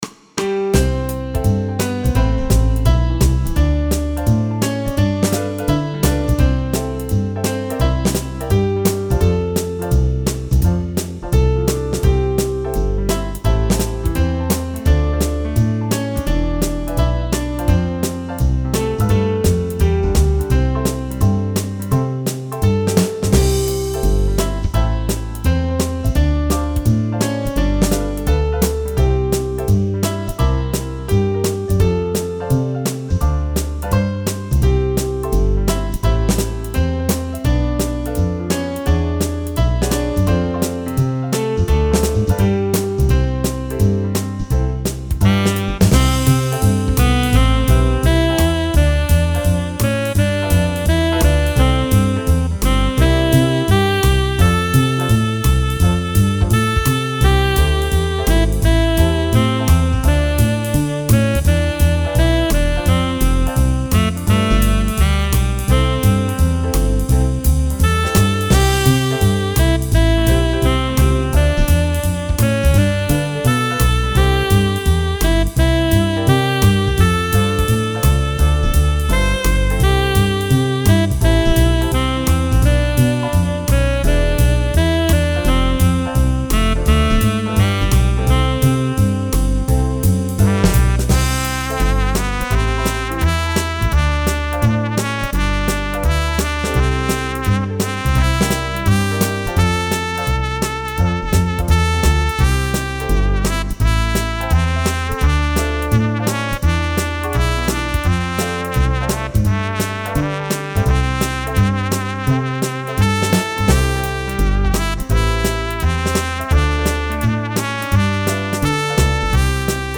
There’s a small band playing in a corner of the lobby, and look!